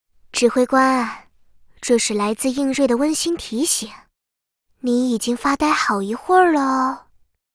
贡献 ） 协议：Copyright，人物： 碧蓝航线:应瑞语音 2022年5月27日